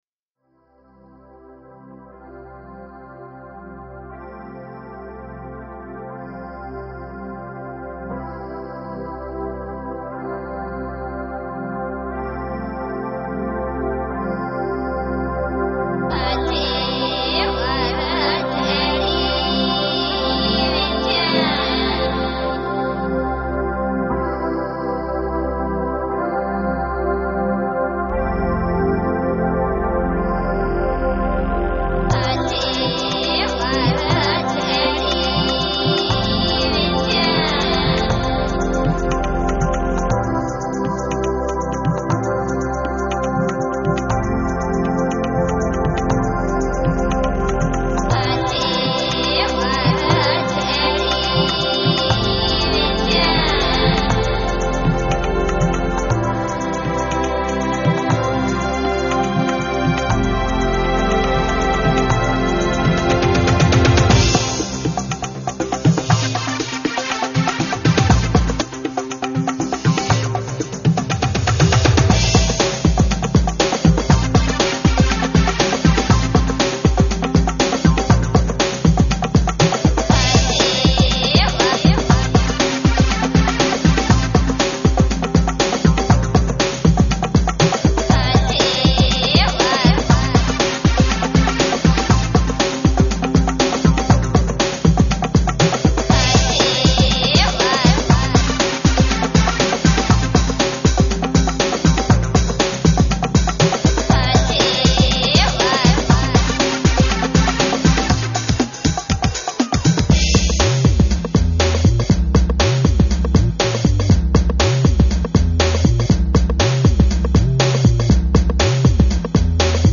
dance techno with an ethno edge